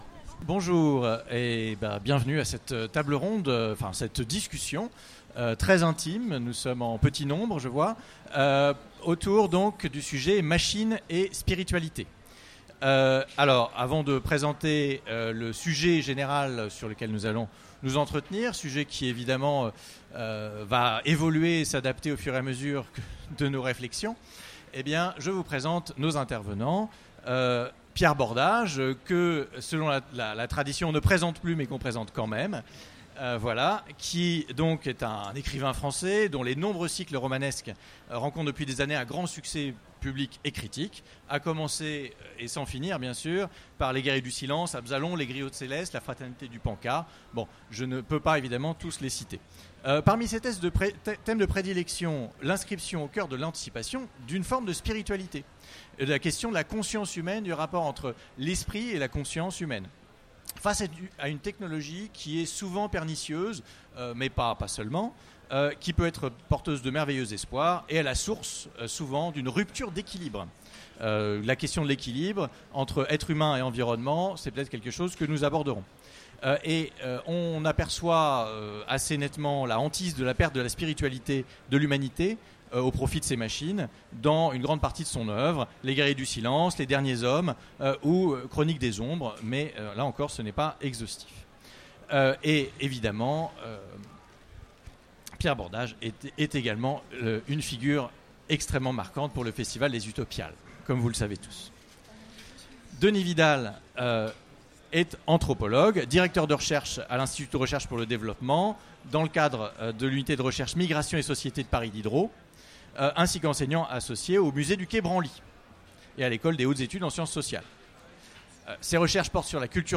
Utopiales 2016 : Conférence Machines et spiritualité